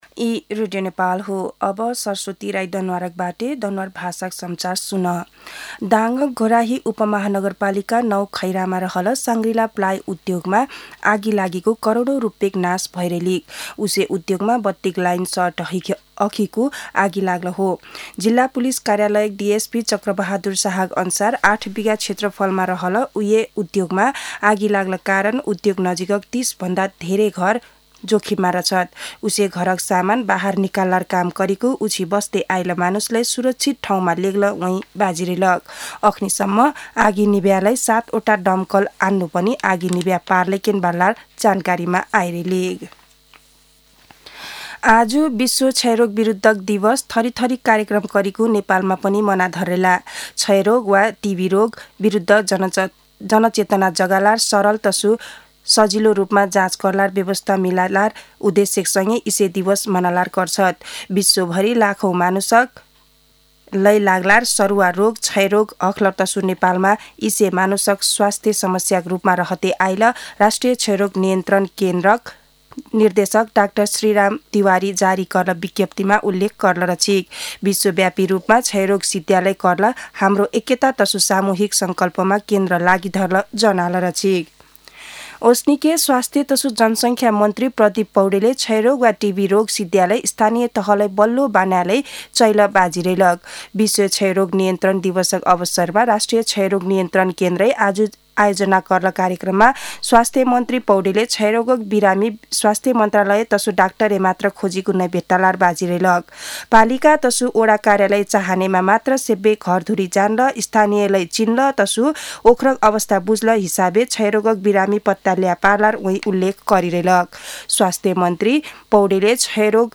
दनुवार भाषामा समाचार : ११ चैत , २०८१
danuwar-news-1-7.mp3